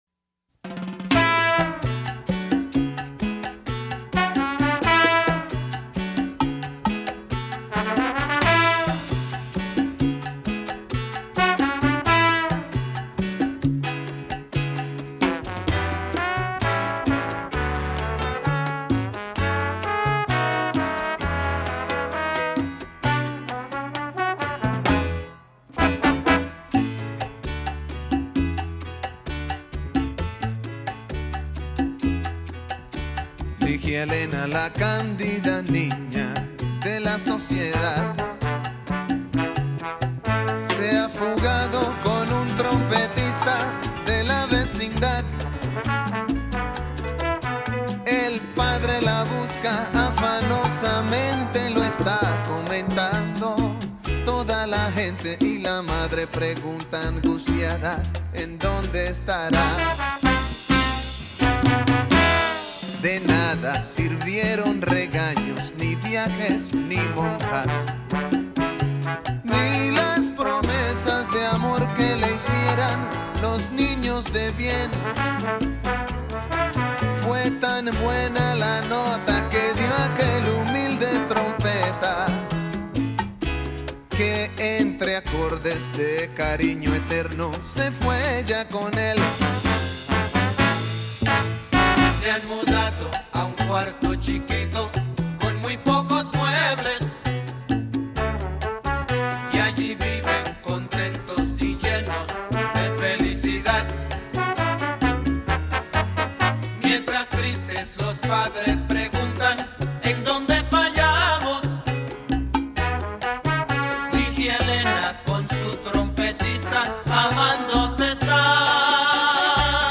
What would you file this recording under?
Genre: Latin